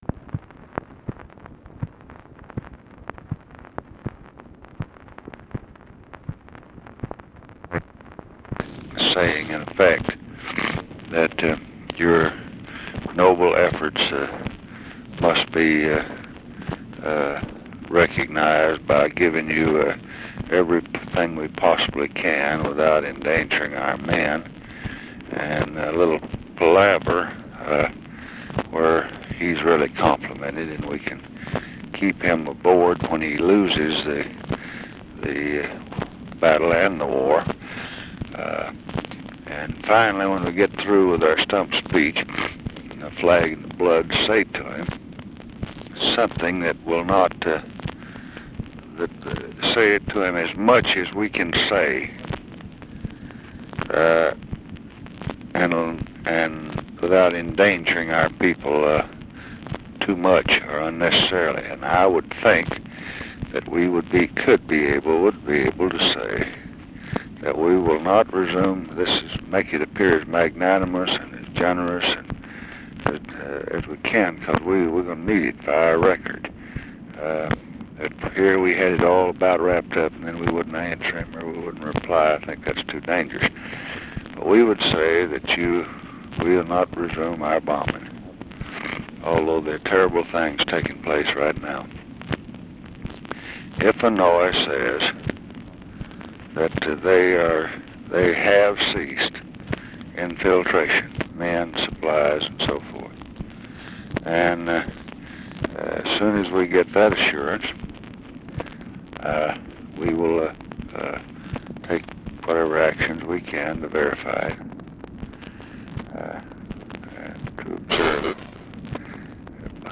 Conversation with ROBERT MCNAMARA, February 13, 1967
Secret White House Tapes